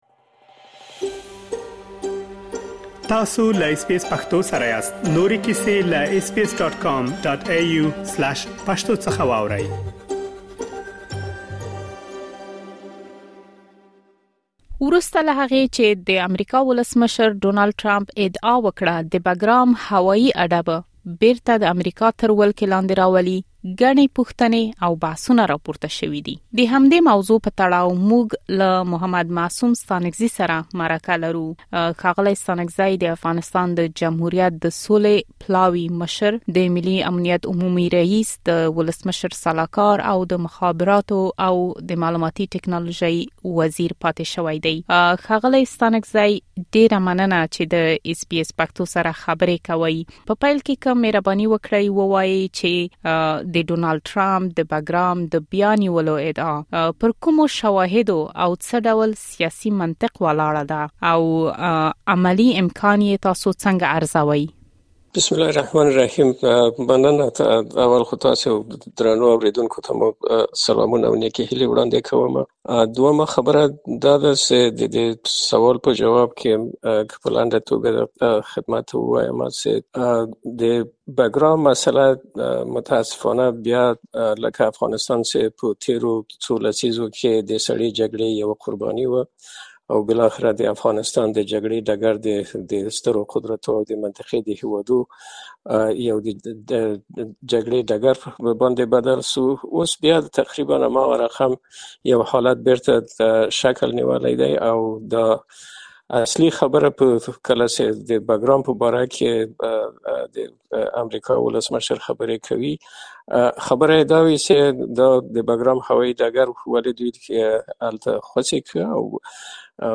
د بګرام هوايي اډې د بېرته نیولو په اړه د امریکا د ولسمشر ډونالډ ټرمپ وروستیو څرګندونو پوښتنې او بحثونه راپورته کړي دي. اس بي اس پښتو د همدغې موضوع په اړه د افغانستان د جمهوریت د سولې پلاوي له مشر او د ملي امنیت له پخواني رئیس محمد معصوم ستانکزي سره مرکه کړې ده.